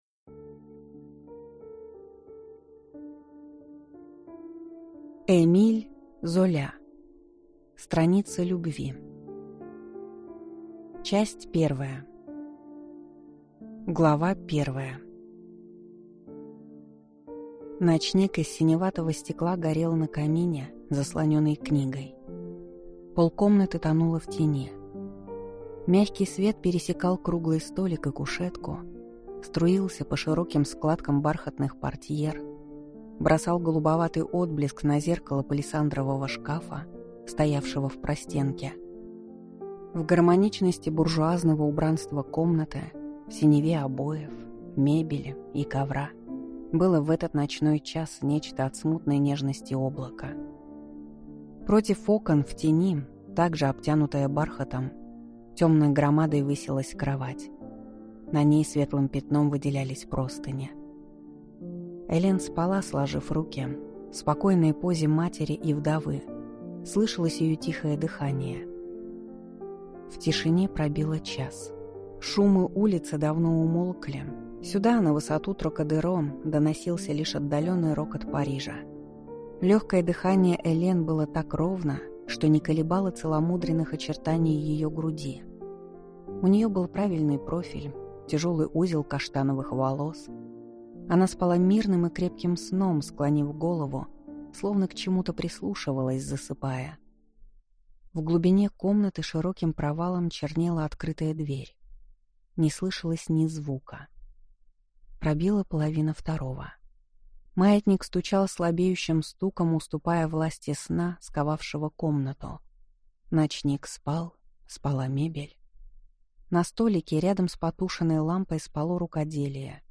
Аудиокнига Страница любви | Библиотека аудиокниг